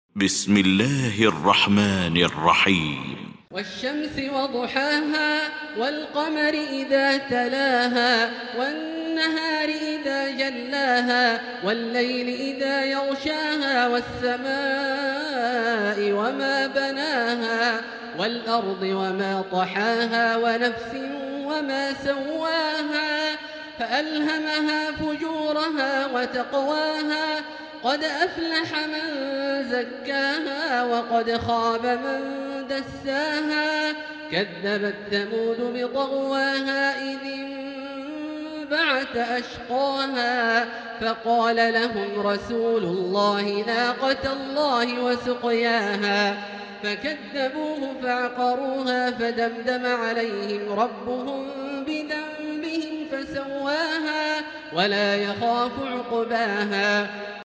المكان: المسجد الحرام الشيخ: فضيلة الشيخ عبدالله الجهني فضيلة الشيخ عبدالله الجهني الشمس